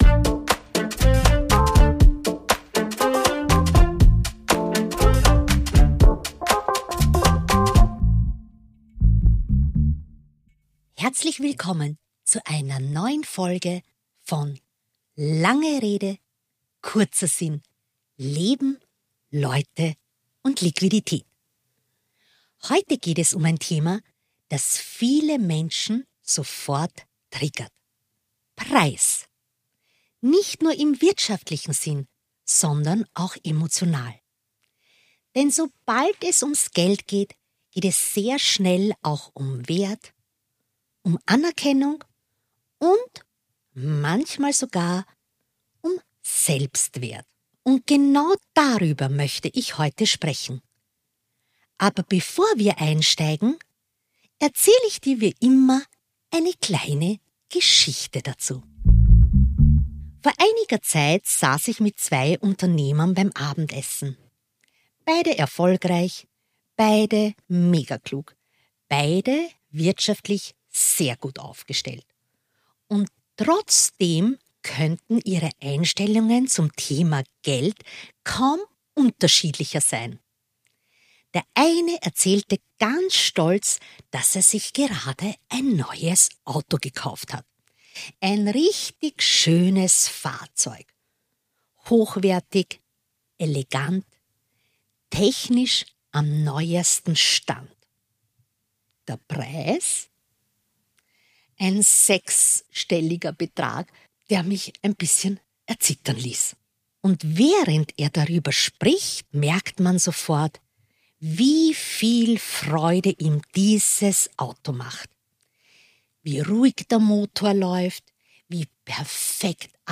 In dieser Solo-Folge spreche ich über einen Widerspruch, den ich seit Jahren beobachte: Wir akzeptieren Preise für Status und Konsum und tun uns schwer, den Wert von Erfahrung, Wissen und guter Arbeit wirklich anzuerkennen.